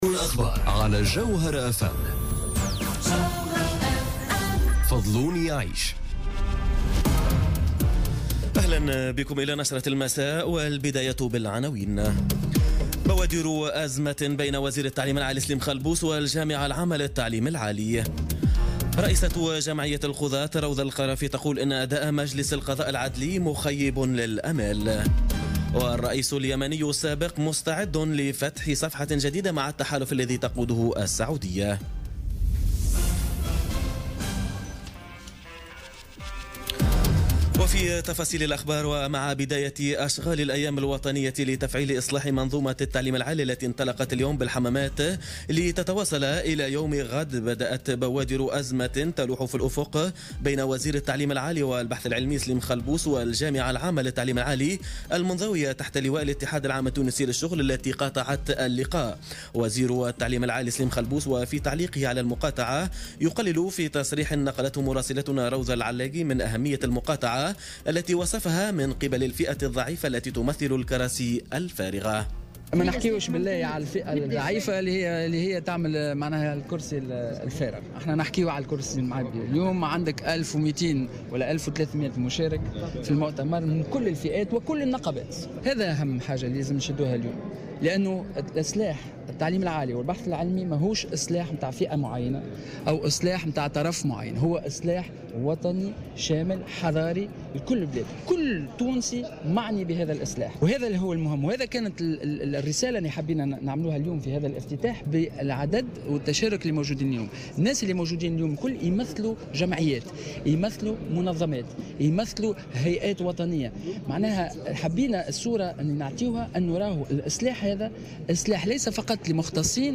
نشرة أخبار السابعة مساء ليوم السبت 2 ديسمبر 2017